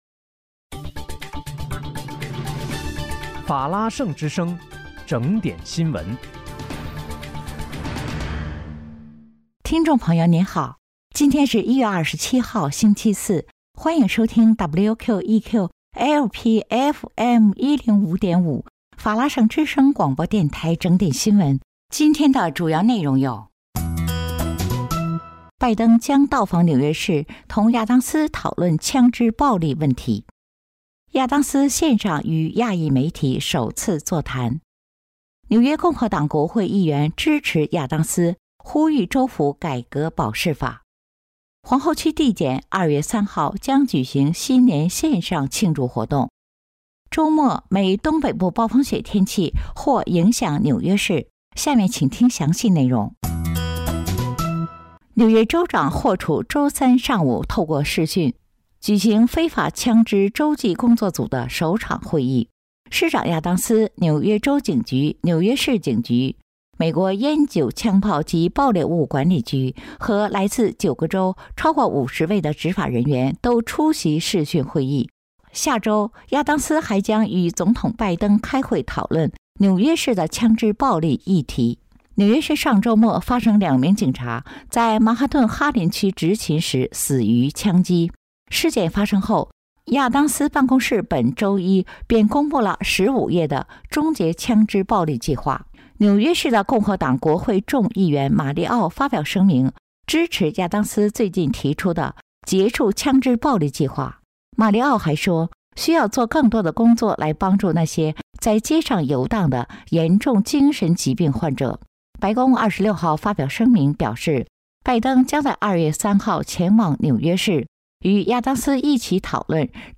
1月27日 ( 星期四）纽约整点新闻